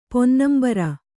♪ ponnambara